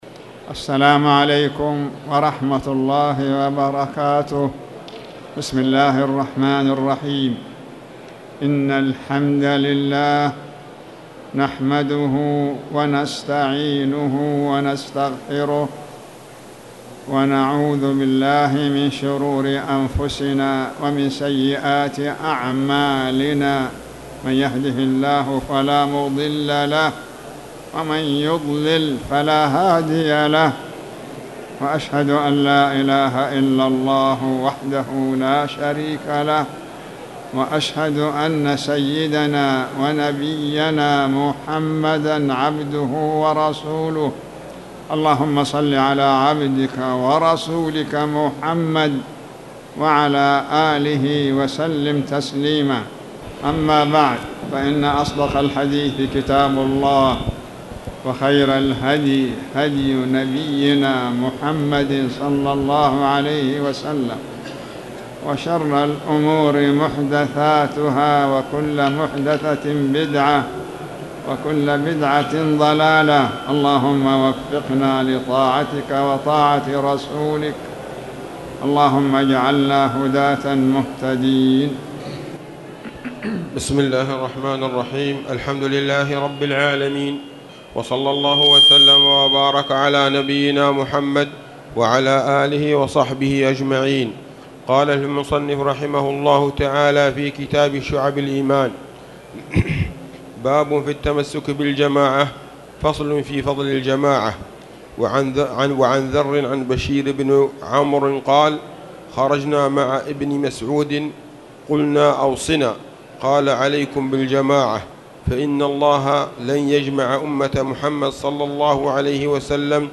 تاريخ النشر ٢٨ ربيع الأول ١٤٣٨ هـ المكان: المسجد الحرام الشيخ